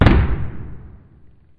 奇怪的世界 爆炸回声
描述：这个声音是通过爆开一个玻璃纸袋做成的，用MAudio Microtrack II录制。这个声音作为烟花或爆炸声听起来非常好，特别是有混响。
Tag: 爆炸 炸弹 热潮 前颠覆者 烟花 响亮 流行 反弹